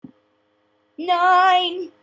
funny voices